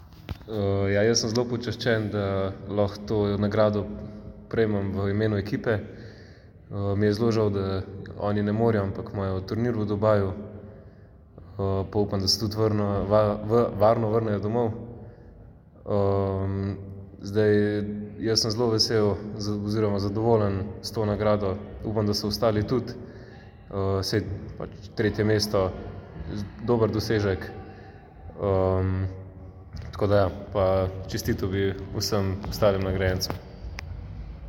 Zvočna izjava